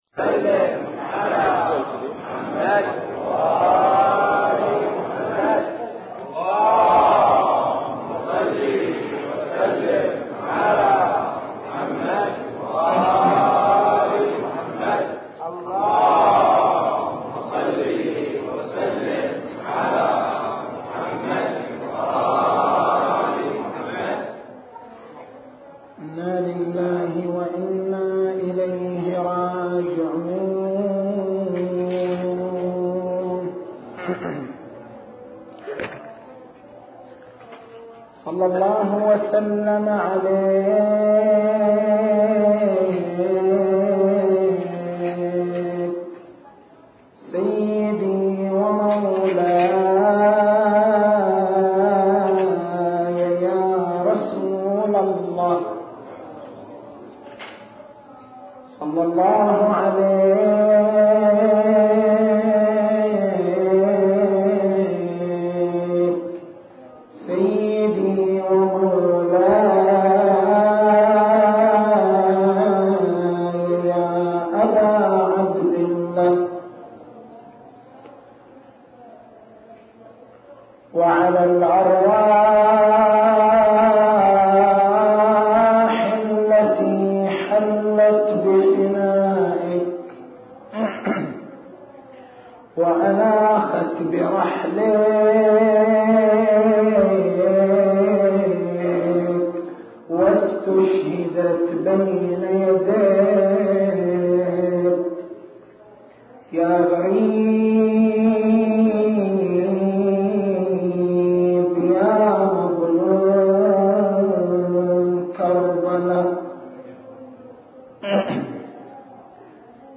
مكتبة المحاضرات
محرم الحرام 1422